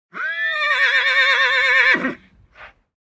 horse_neigh2.ogg